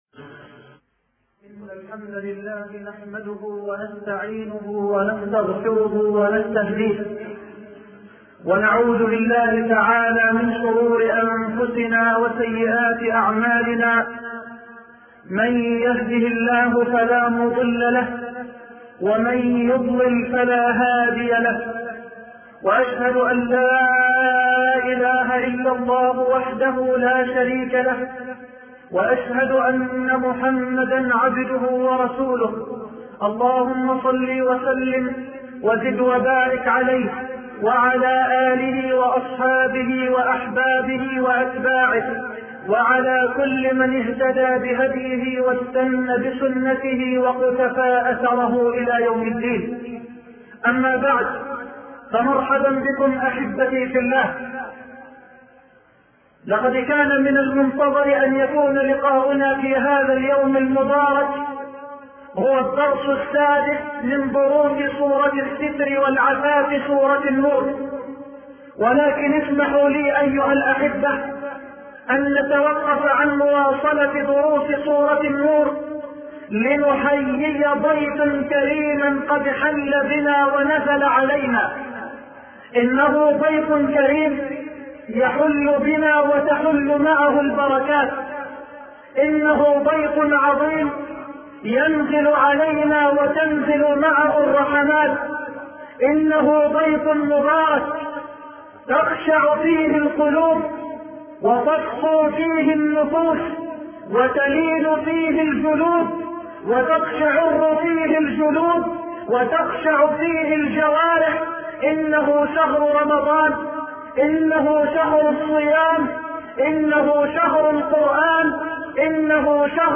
شبكة المعرفة الإسلامية | الدروس | رمضان تجارة رابحة |محمد حسان